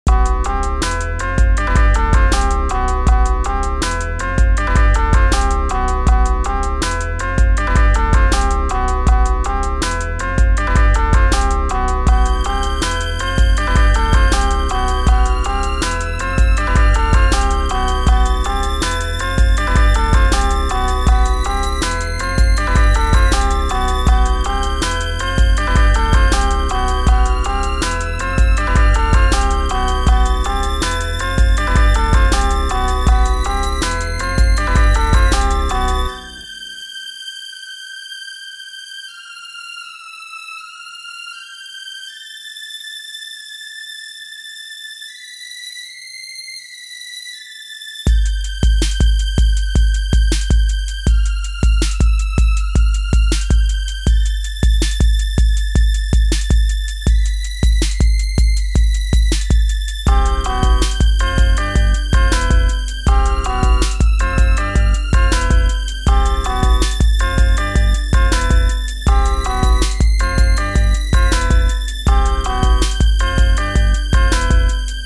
memphis horrorcore